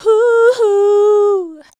UUHUU.wav